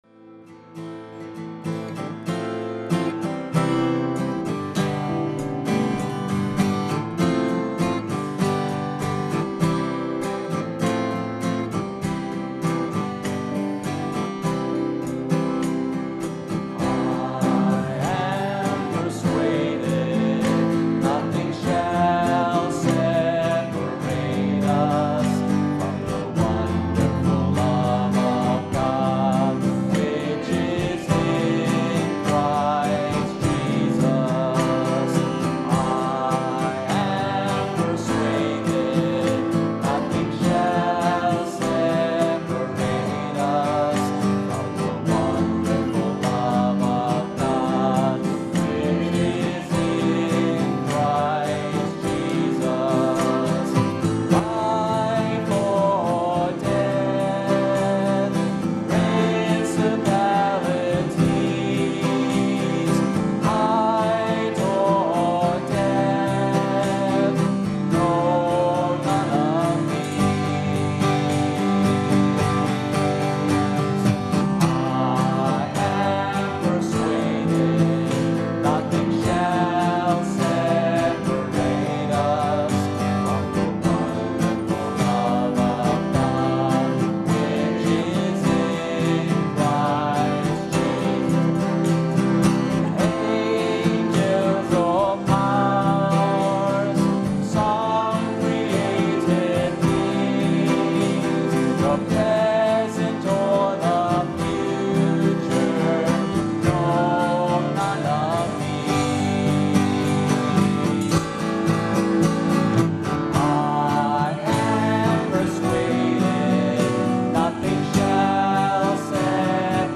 [Karaoke Video with vocal]